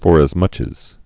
(fôrəz-mŭch əz)